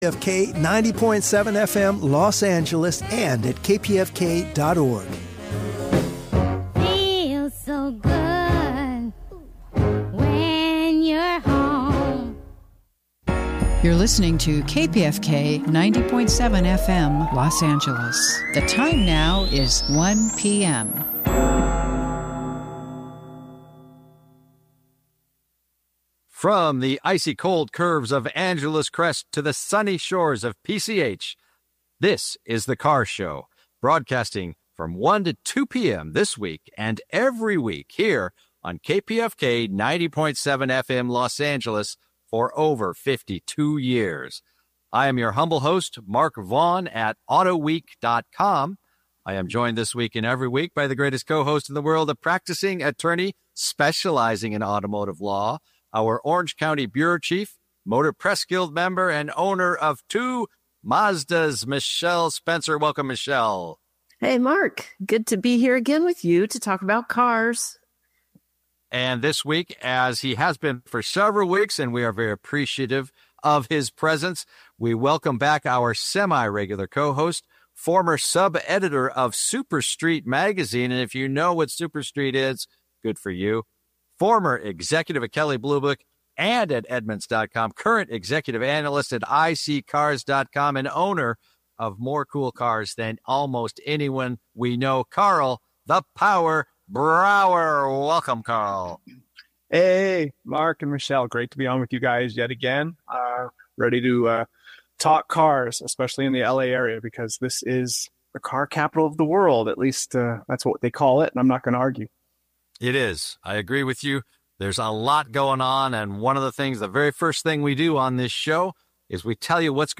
Everything about the automotive world including listener call-ins, event calender, industry news, racing news,new products, vehicle road tests,classic and collector vehicles, in-studio and call-in guests on all automotive and related subjects---and it has been on since 1973.